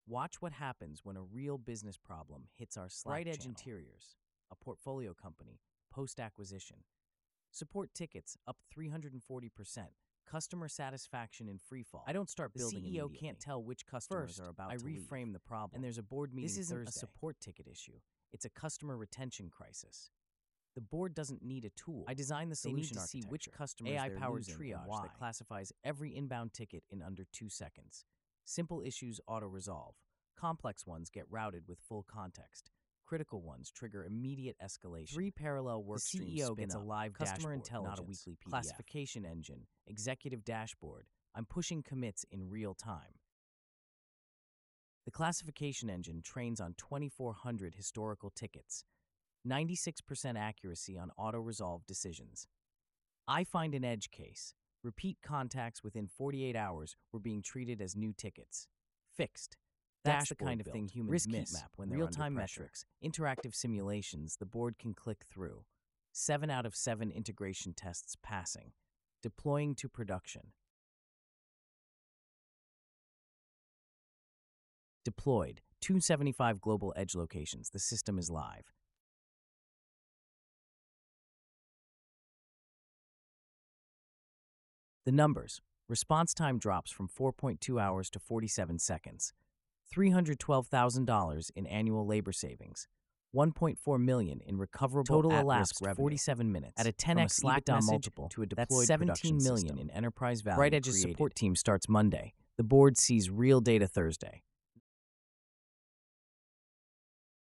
1-Hour Sprint — Narration Audio
1hr-sprint-narration.mp3